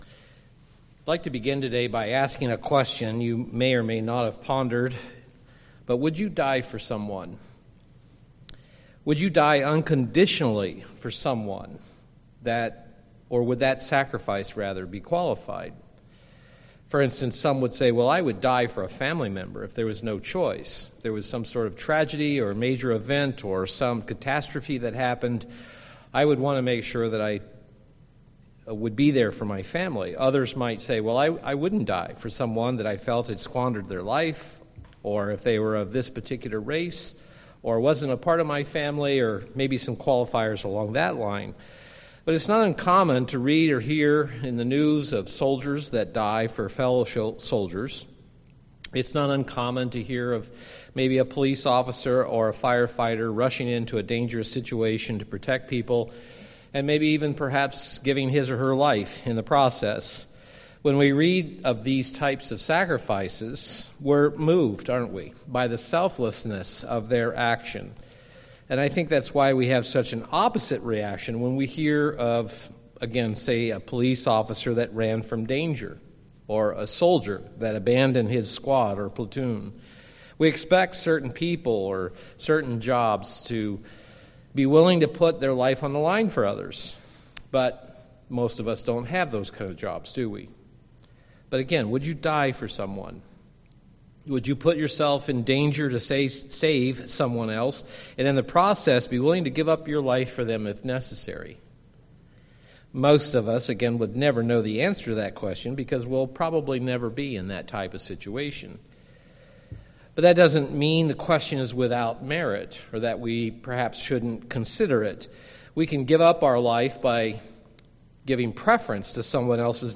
Given in Milwaukee, WI
UCG Sermon Passover sacrifice marriage marriage and commitment commitment covenant covenant loyalty Studying the bible?